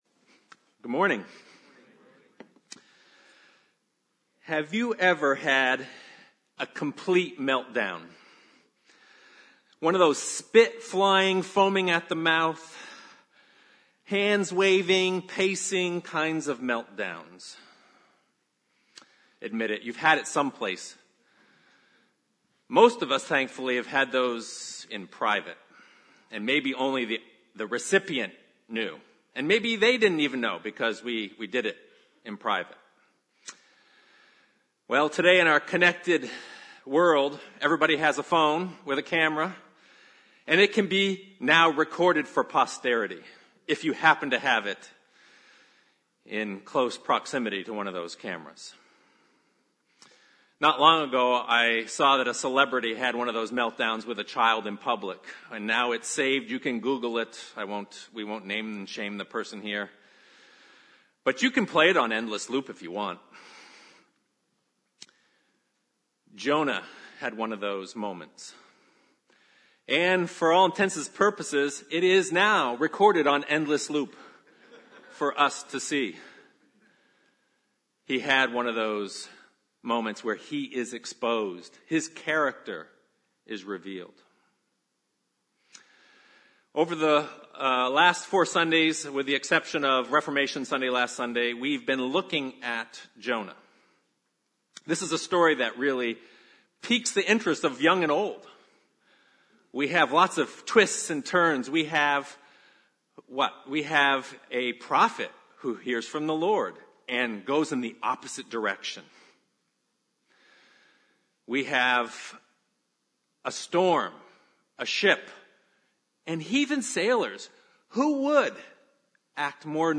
I preached. In the sermon I explore Jonah’s anger and our anger about injustice. I point out that the problem is not that Jonah is angry but that he is hardened and blinded.
jonah-4-sermon.mp3